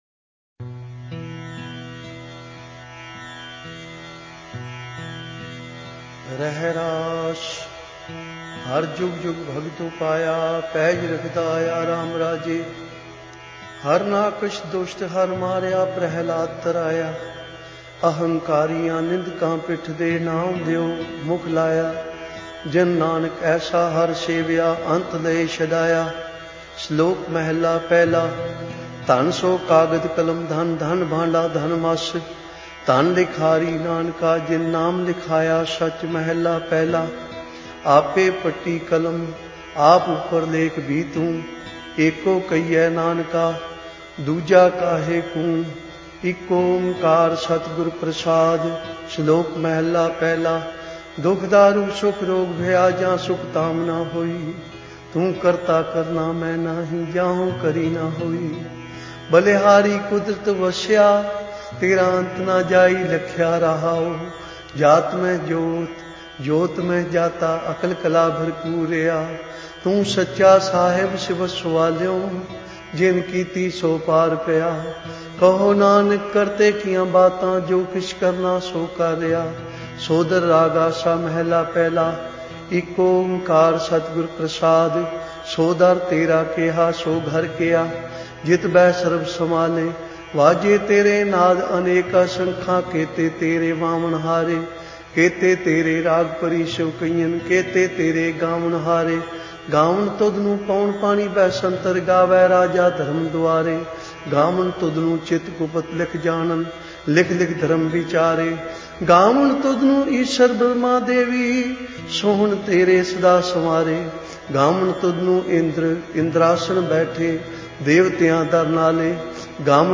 Genre: Gurbani Ucharan